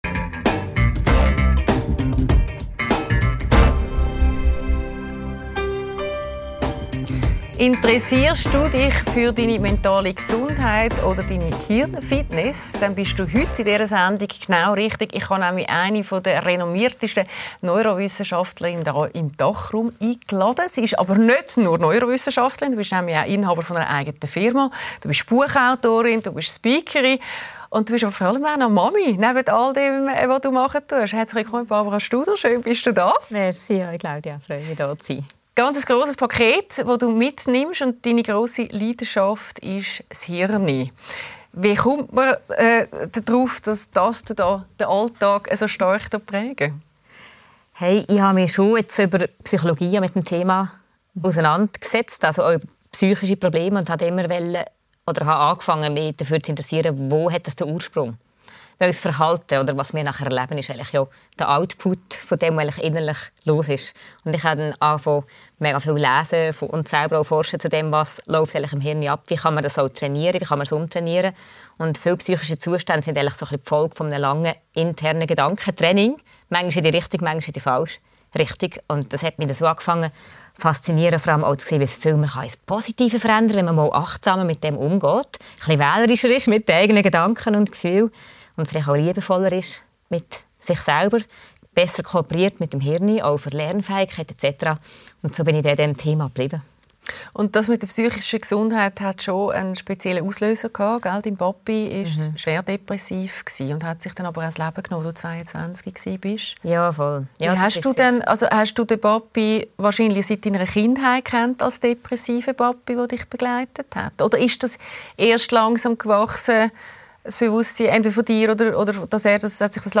Die Talkshow